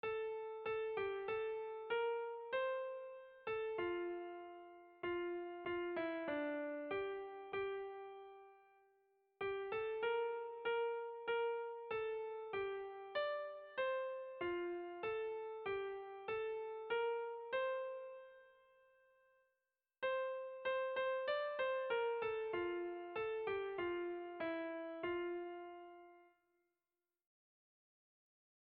Erlijiozkoa
Kantu hau, herriak eta bakarlariak, txandaka kantatzeko egina da, honen antzeko beste asko bezala; bi lehenengo puntuak bakarlariak eta hirugarrena, erritmo librean herriak kantatzeko egina.
ABD